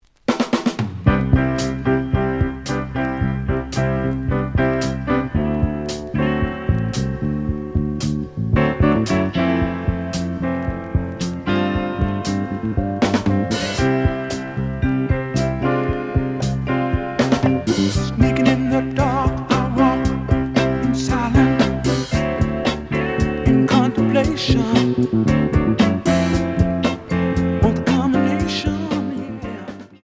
(Mono)
試聴は実際のレコードから録音しています。
●Genre: Soul, 70's Soul